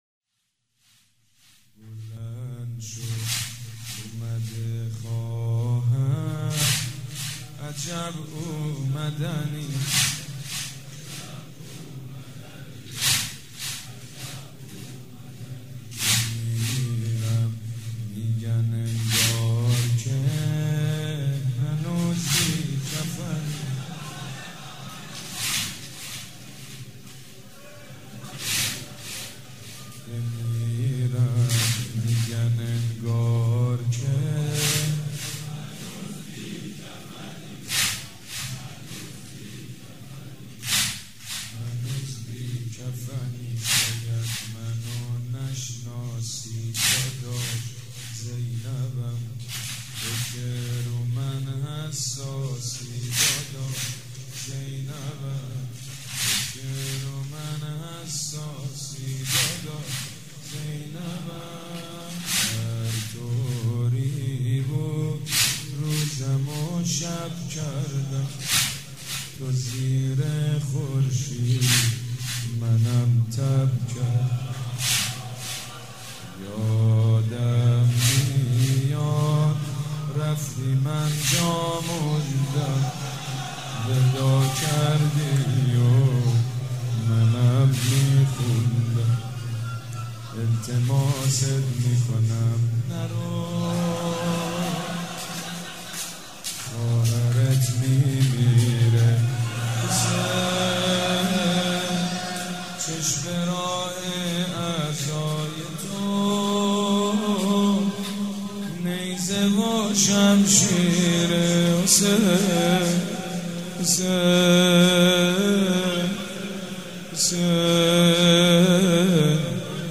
مداحی اربعین